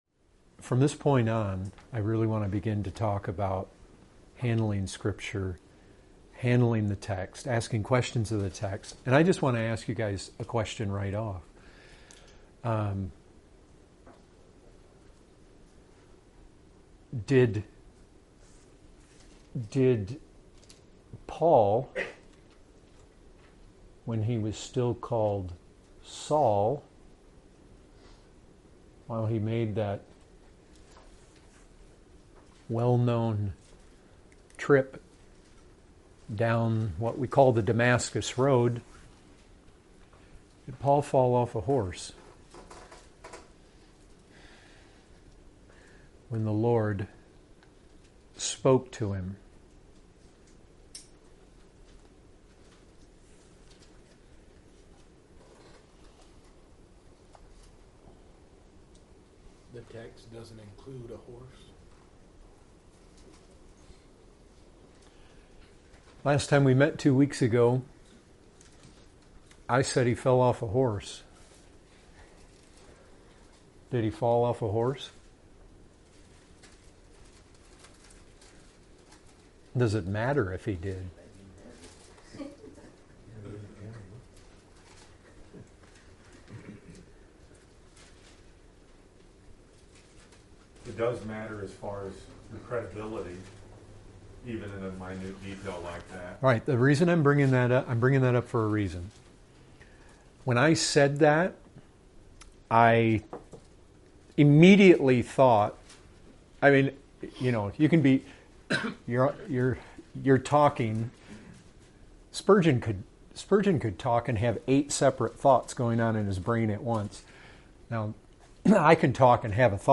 Category: Bible Studies